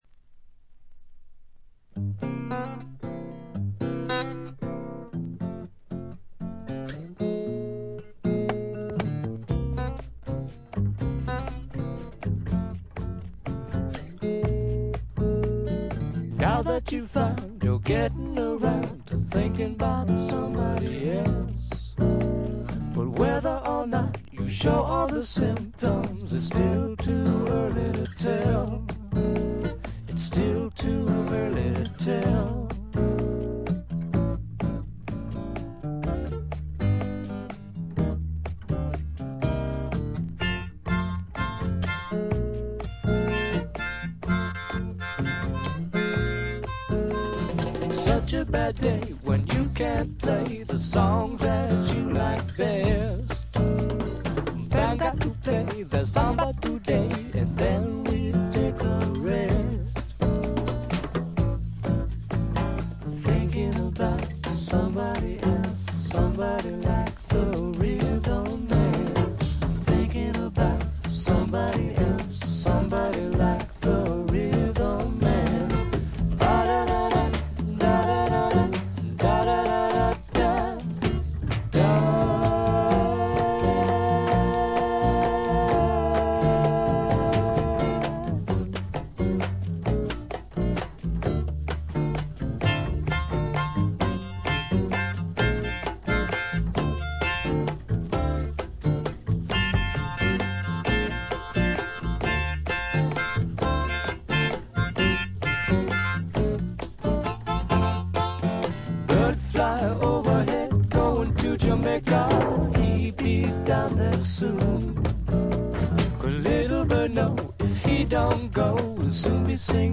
細かなチリプチや散発的なプツ音は少し出ますが良好に鑑賞できます。
内容はいかにもボストンらしく、フォーク、ドリーミー・ポップ、グッタイム・ミュージック等を下地にした実にごきげんなもの。
試聴曲は現品からの取り込み音源です。